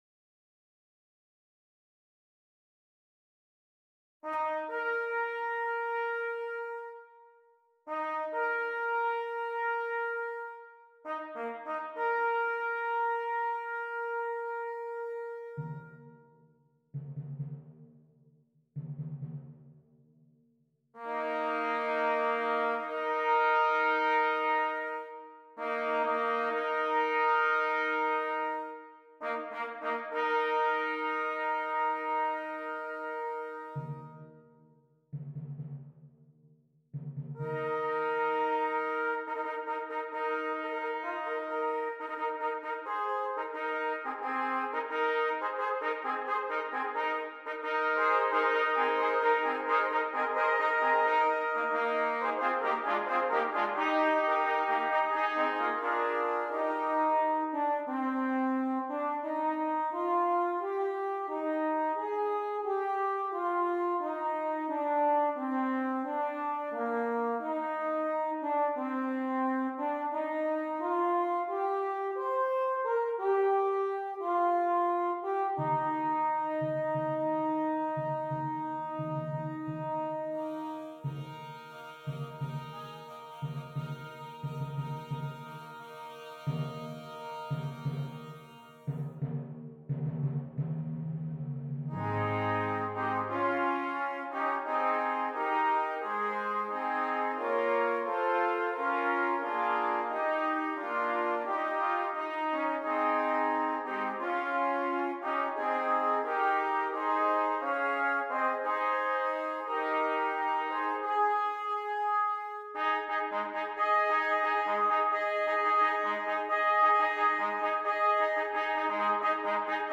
12 Trumpets and Timpani
Traditional